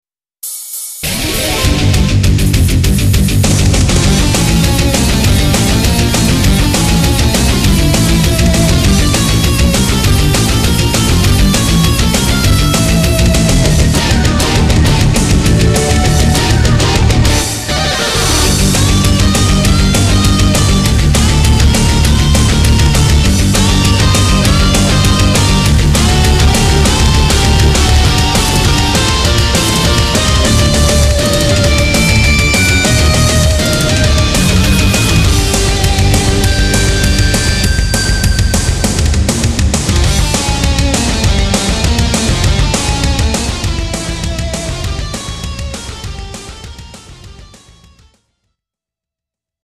音源モジュール YAMAHA MU2000